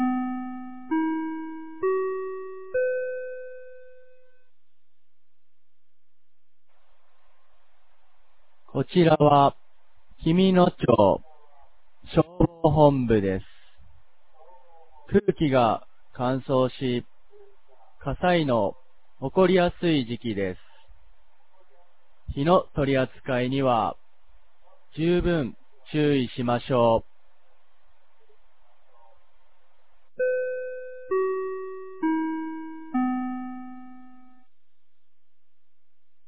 2024年01月20日 16時00分に、紀美野町より全地区へ放送がありました。
放送音声